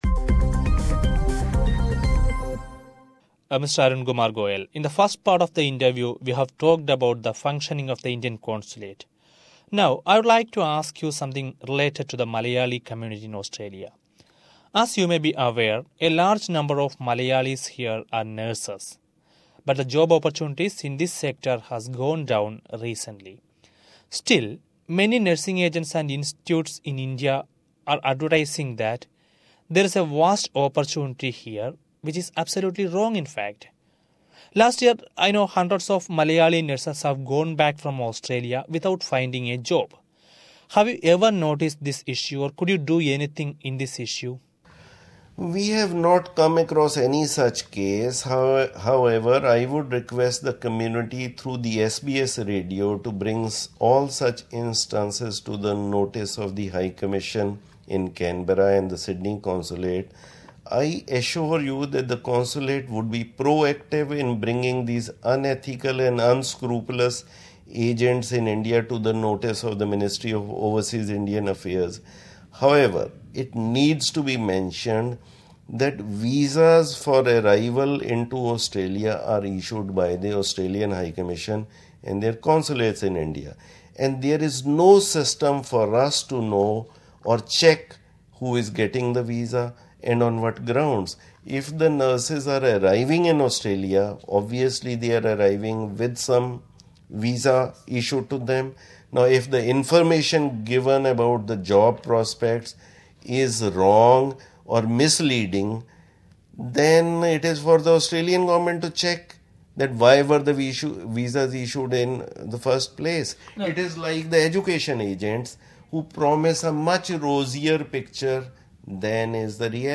SBS Malayalam spoke to Arun Goel, the Indian Consul General in Sydney last week. Let us now listen to the second part of this interview, where he answers to the issues of Malayalee nurses in Australia...